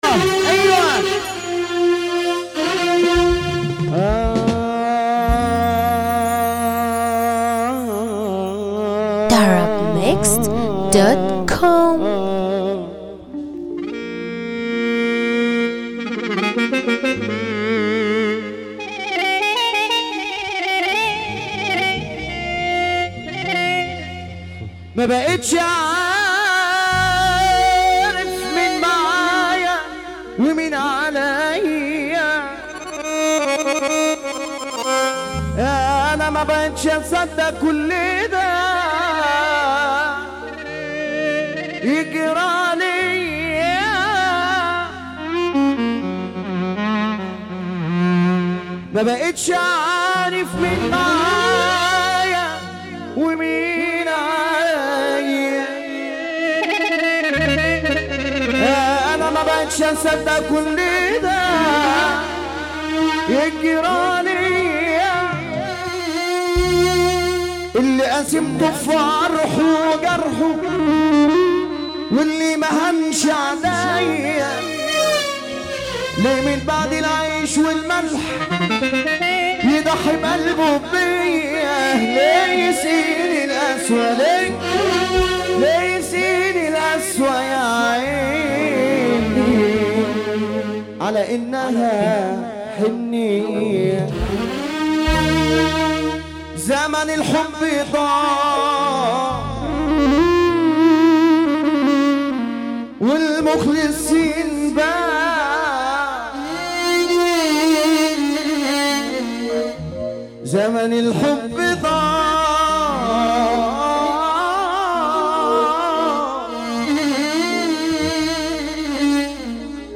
موال
حزين